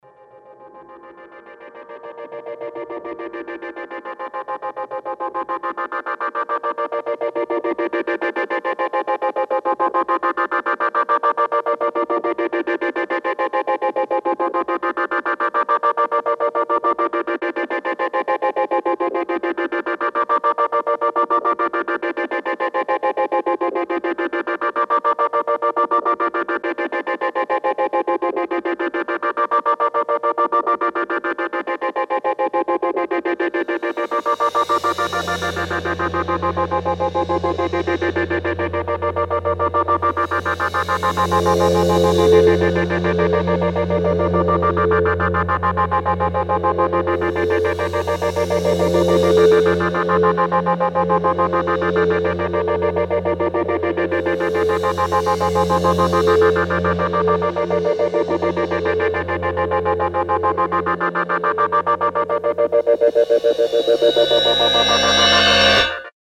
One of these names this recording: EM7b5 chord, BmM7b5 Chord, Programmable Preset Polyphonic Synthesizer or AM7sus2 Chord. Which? Programmable Preset Polyphonic Synthesizer